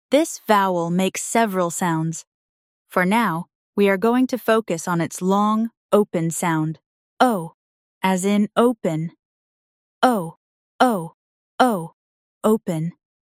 For now, we are going to focus on its long, open sound, /ō/, as in “open”.
O-open-lesson.mp3